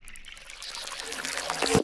TM88 NastyWaterFX.wav